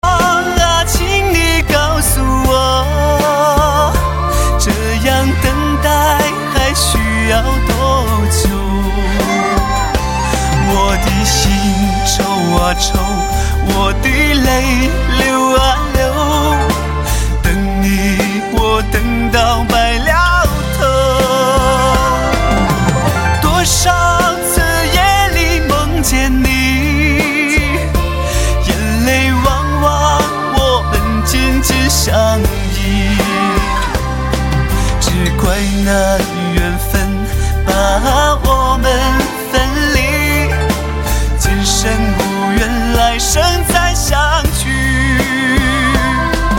M4R铃声, MP3铃声, 华语歌曲 69 首发日期：2018-05-16 01:06 星期三